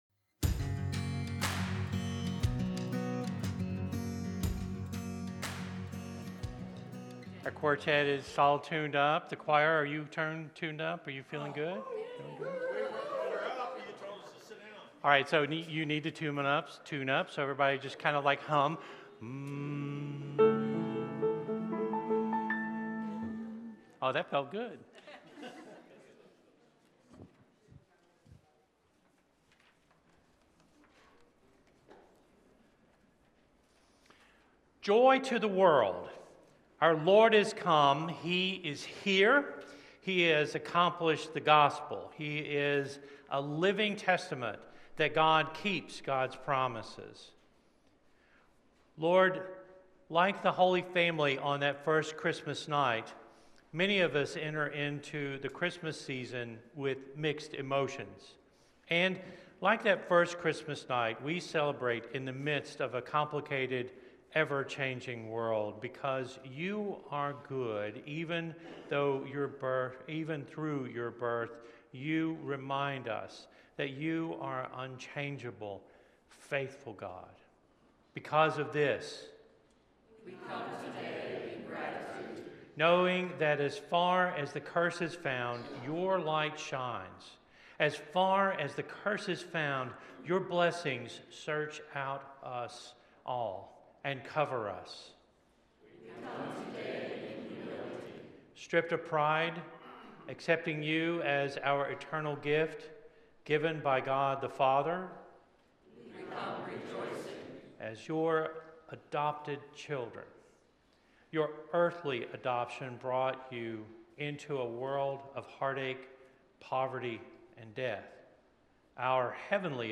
Today, as we gather for this service of Lessons and Carols, may the story of Christ’s coming fill us with the courage to hope. Through Scripture, song, and prayer, let us risk believing that God’s light can and will transform our world.